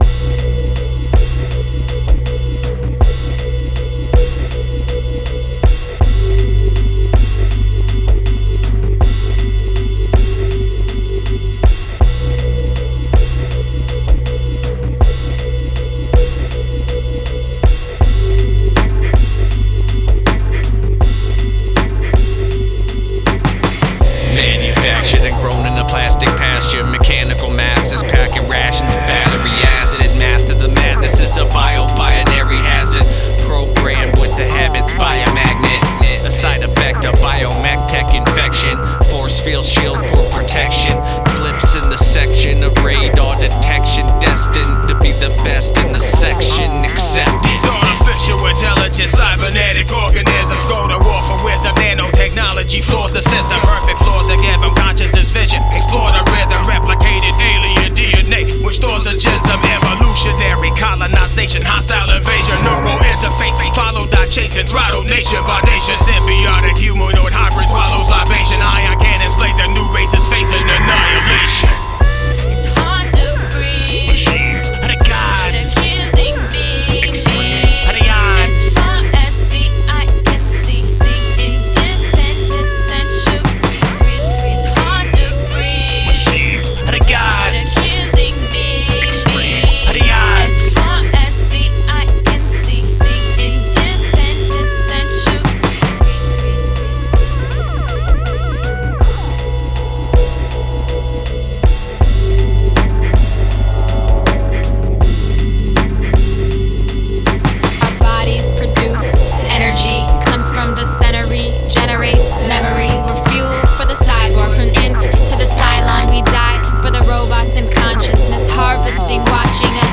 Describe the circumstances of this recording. Recorded at AD1 Studios, The Operating Room